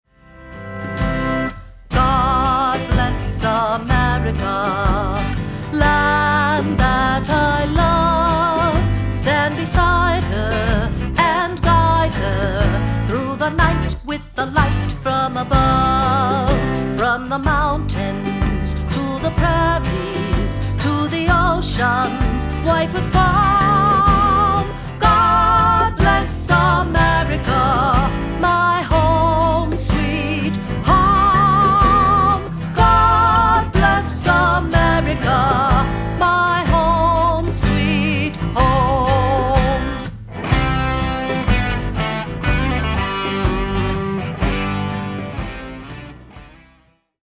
Vocals
Guitar, bass, organ, and percussion